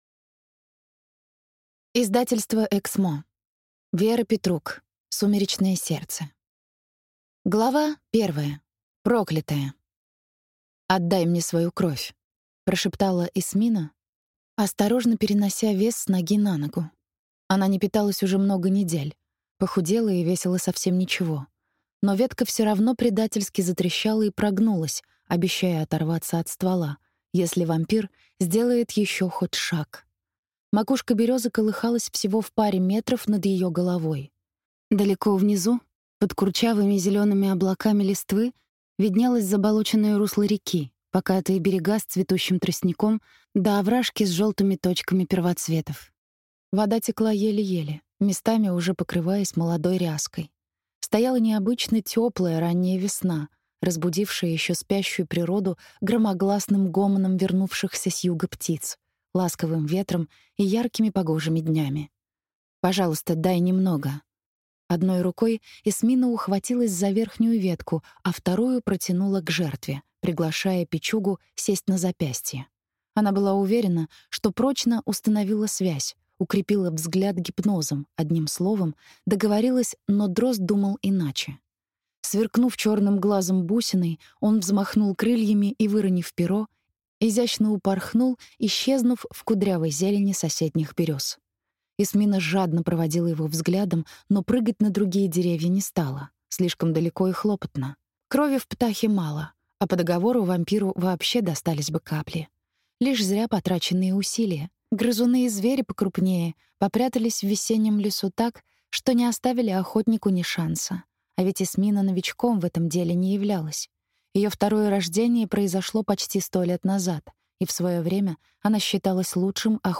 Аудиокнига Сумеречное сердце | Библиотека аудиокниг